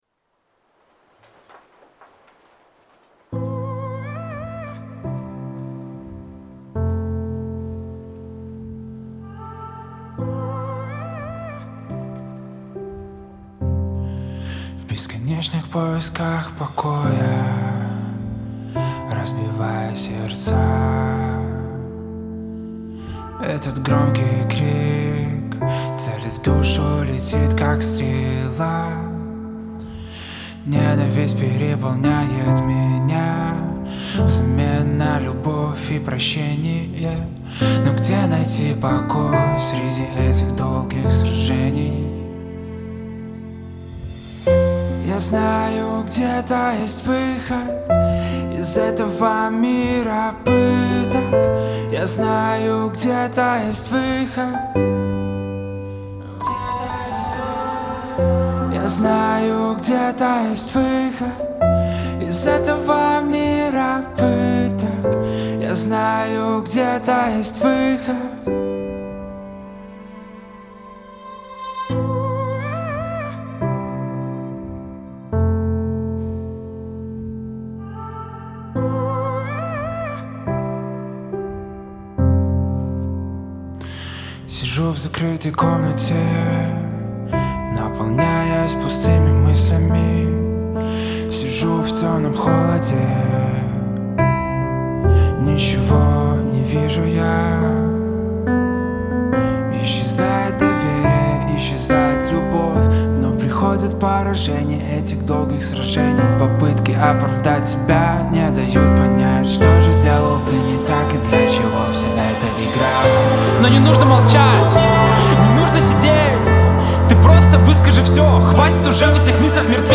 2158 просмотров 789 прослушиваний 357 скачиваний BPM: 140